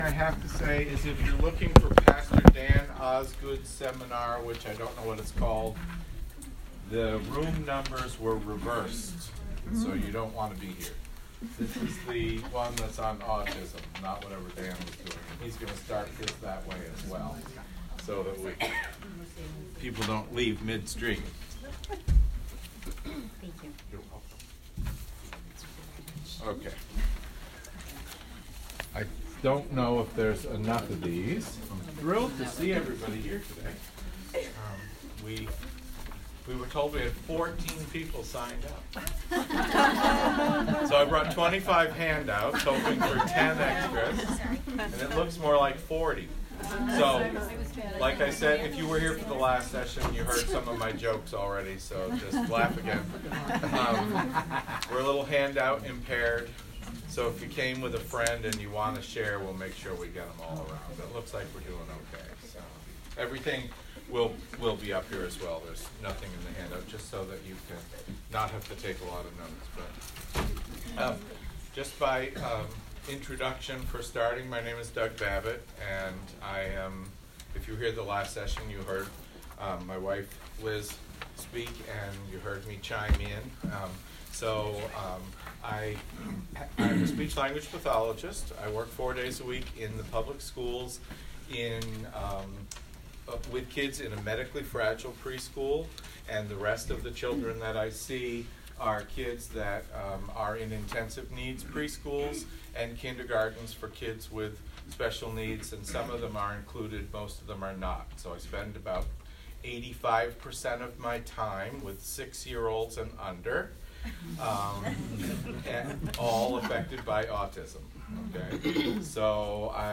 Disability and the Gospel Conference